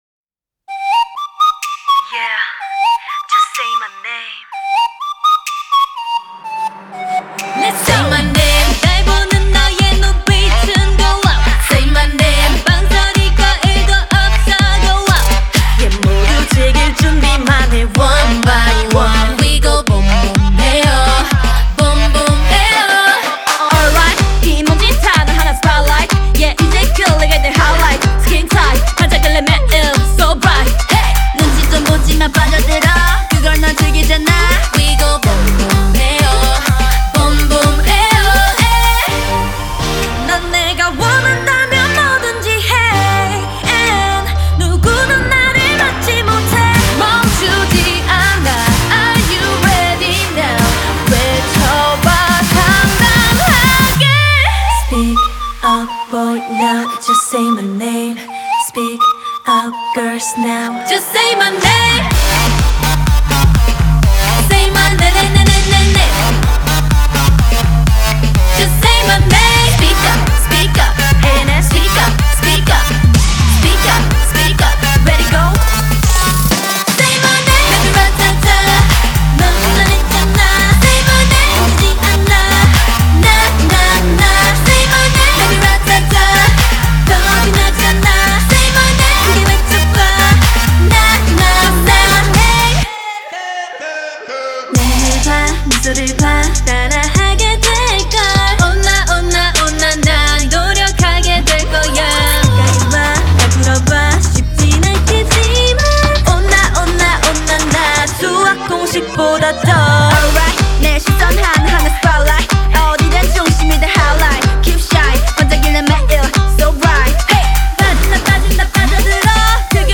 это энергичная поп- и R&B-композиция